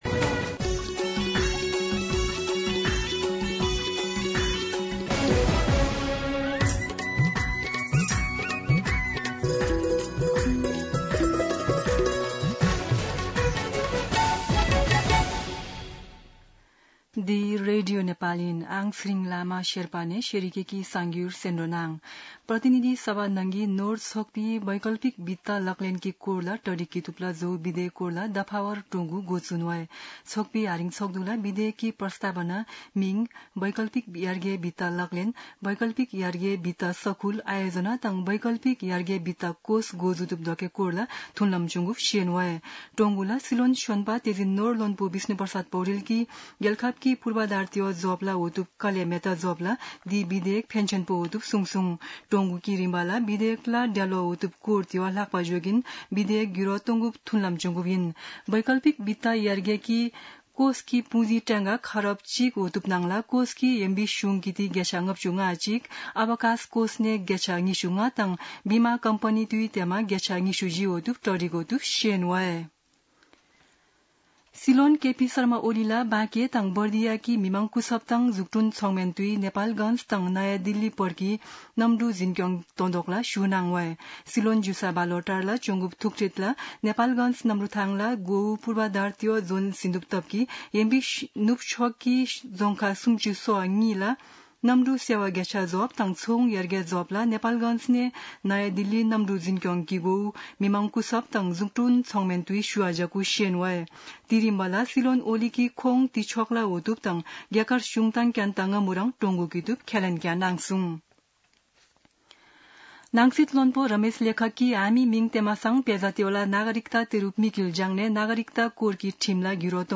शेर्पा भाषाको समाचार : २६ साउन , २०८२
Sherpa-News-1-1.mp3